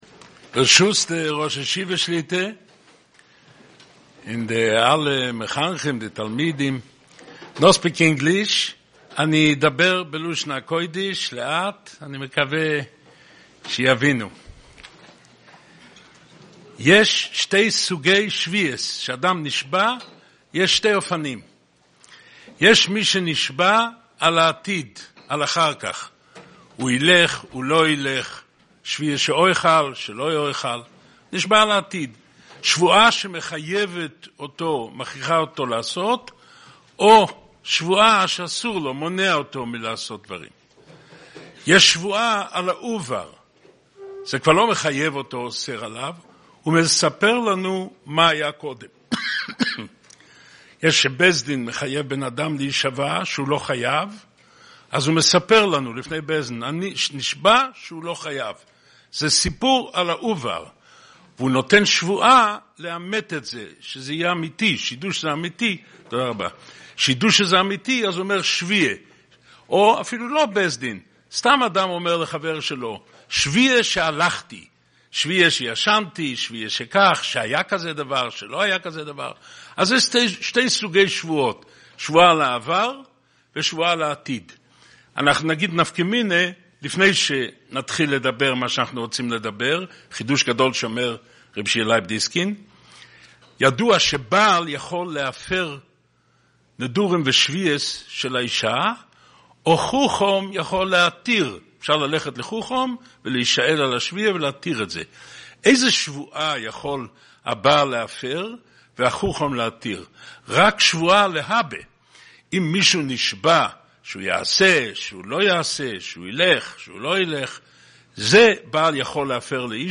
Special Shiur - Ner Israel Rabbinical College
שיעור בישיבת נר ישראל בולטימור